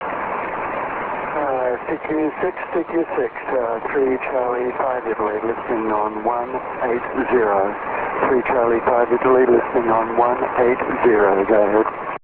3C5I realaudioVERY STRONG!!! (all Italy) at 1400-1500z